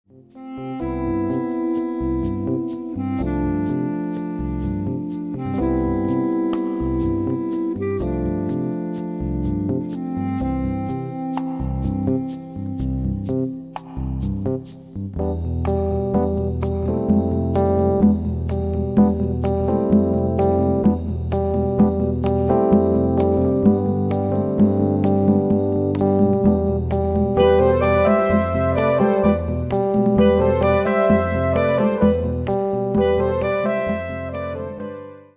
was to have the music evolve in the studio,
(electric piano, clarinet, electronic keyboards, percussion)